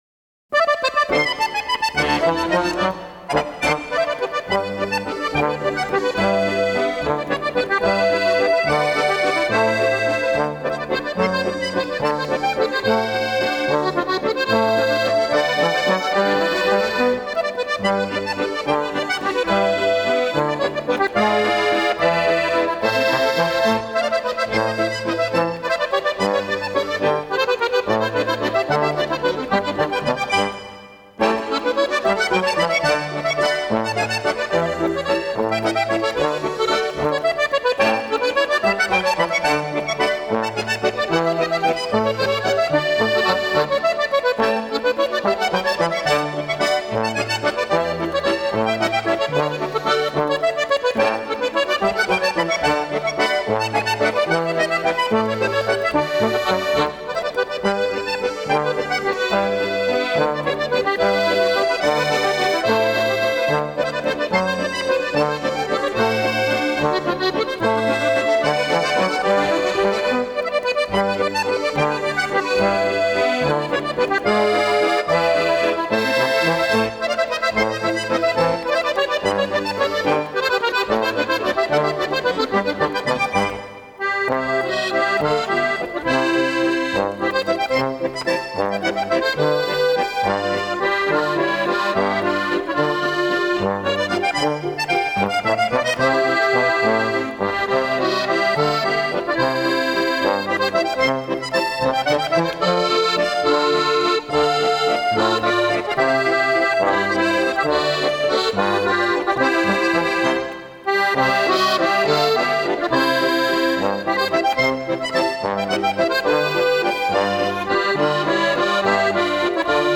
valse/Waltz